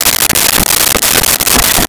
Saw Wood 01
Saw Wood 01.wav